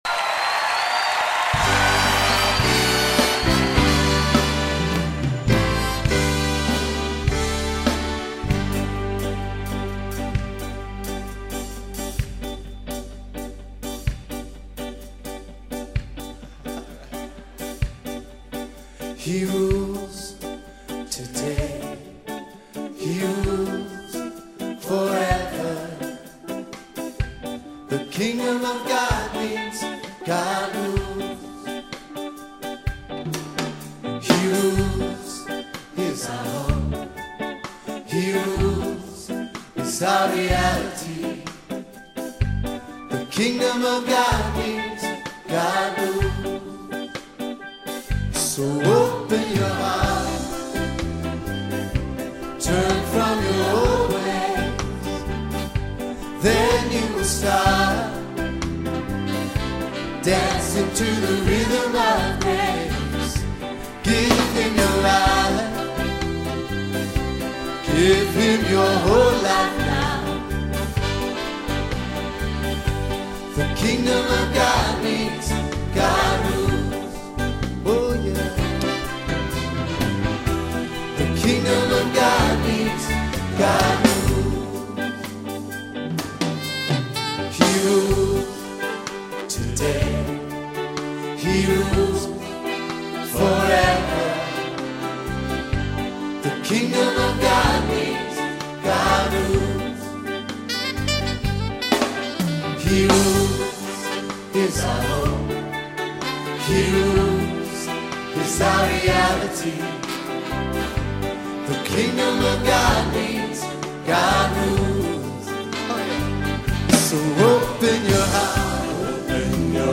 Spiritual Song